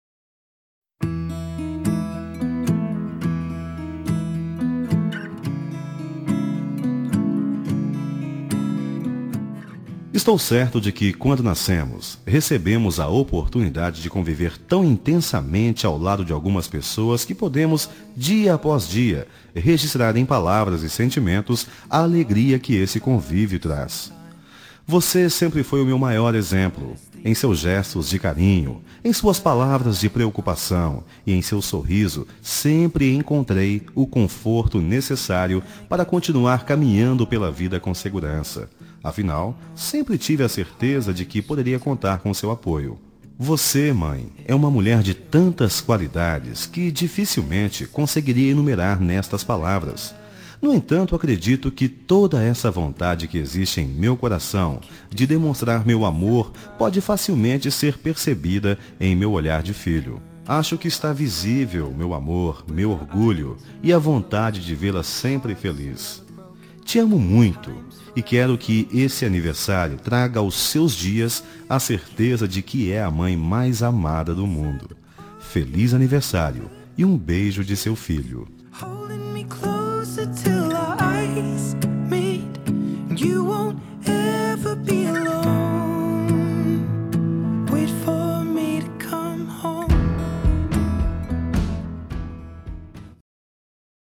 Telemensagem de Aniversário de Mãe – Voz Masculina – Cód: 1426 – Linda